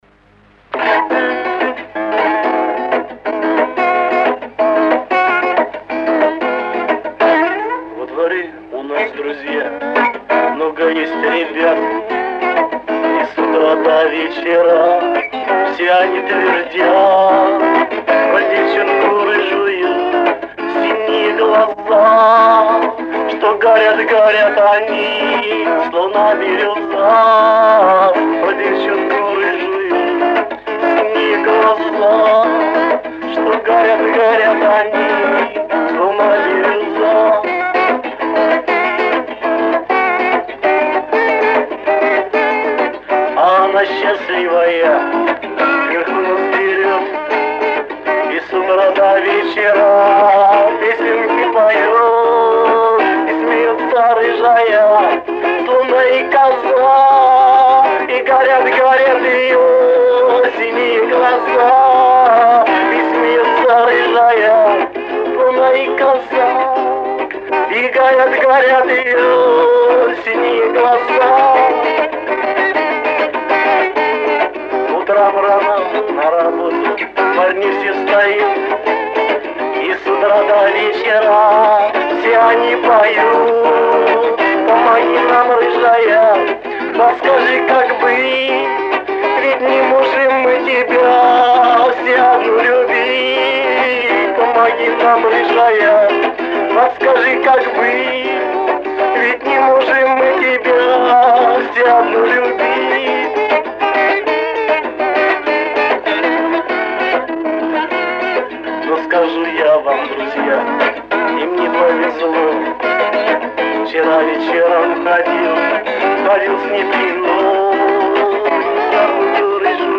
У кого есть вот эта дворовая песня поделитесь пожалуйста...